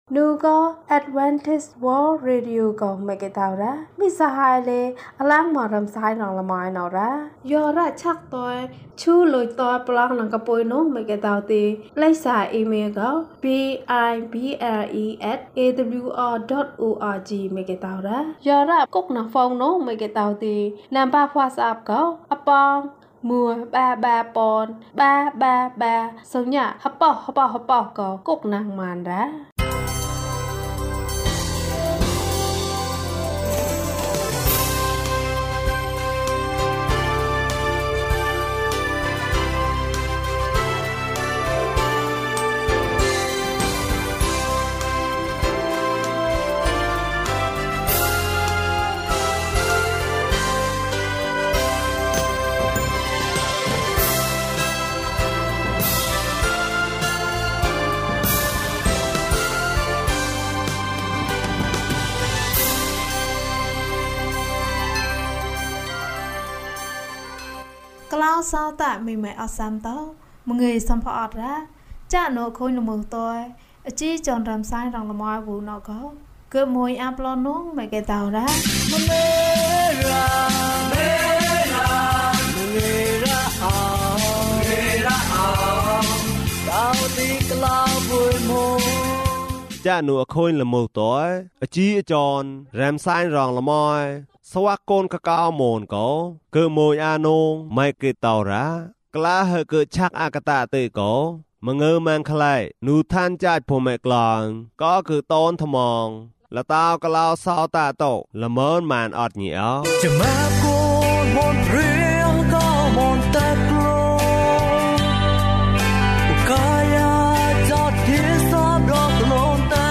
ခရစ်တော်ထံသို့ ခြေလှမ်း ၂၄။ ကျန်းမာခြင်းအကြောင်းအရာ။ ဓမ္မသီချင်း။ တရားဒေသနာ။